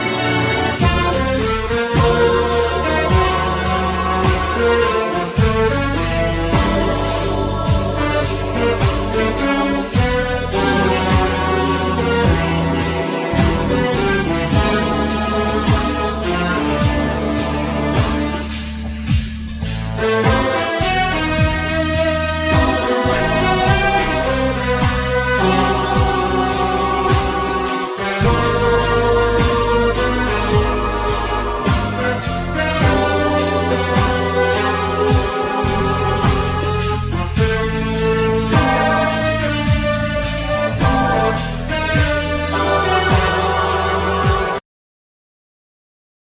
Fender Bass,Double Bass
Keyboards,Organ,Grand Piano
Saxophne
Percussion